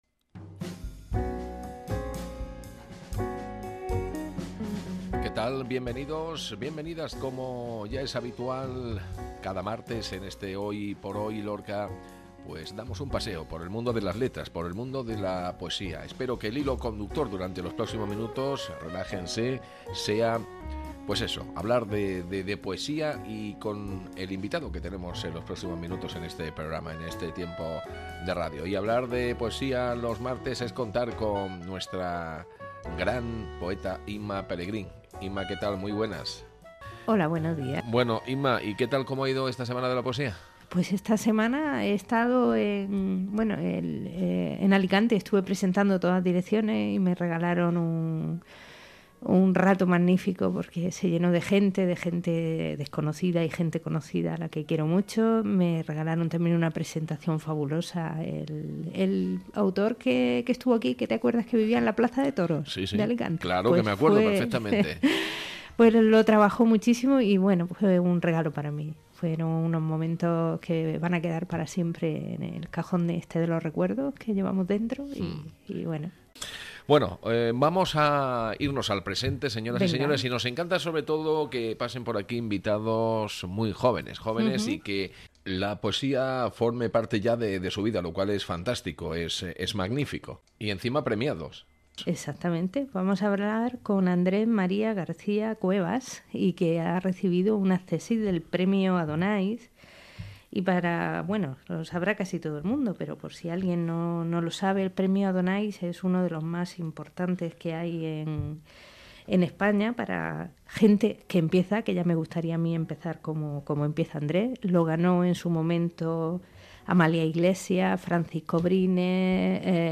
Descarga: Audio de la entrevista